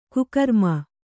♪ kukarma